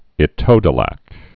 (ĭ-tōdə-lăk)